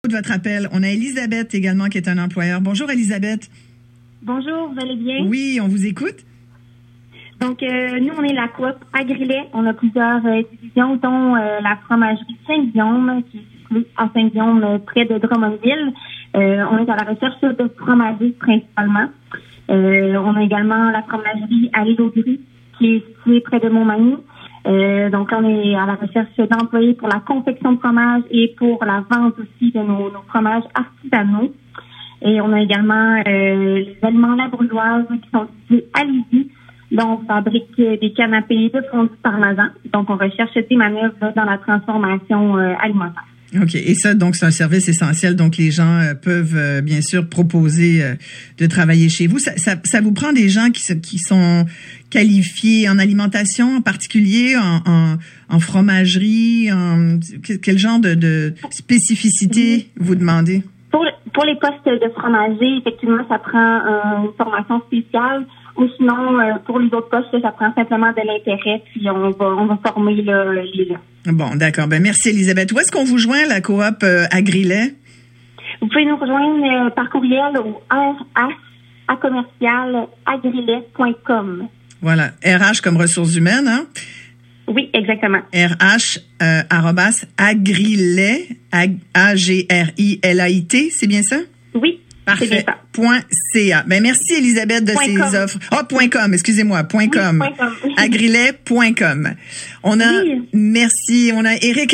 En cette période de crise reliée à la COVID-19, la Coop Agrilait s’est entretenue ce matin avec l’animatrice Isabelle Maréchal à la radio du 98,5 FM alors qu’elle cédait son micro aux employeurs de services essentiels qui ont des emplois à combler.